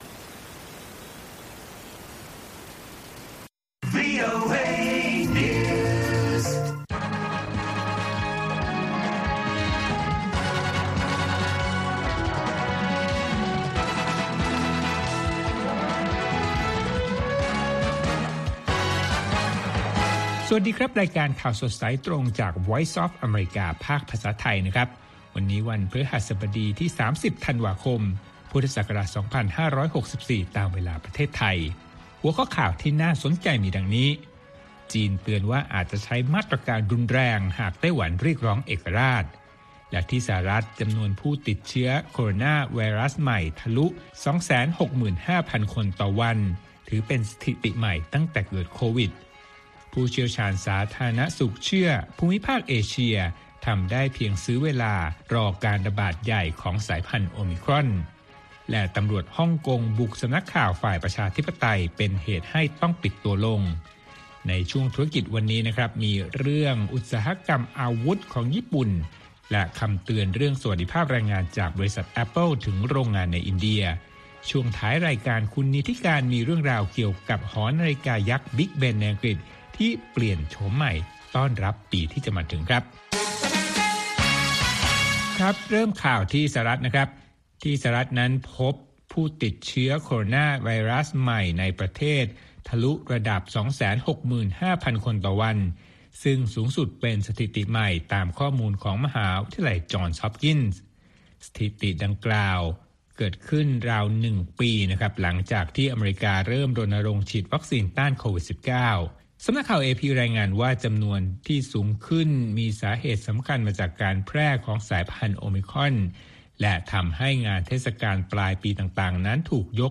ข่าวสดสายตรงจากวีโอเอ ภาคภาษาไทย 8:30–9:00 น. ประจำวันพฤหัสบดีที่ 30 ธันวาคม2564 ตามเวลาในประเทศไทย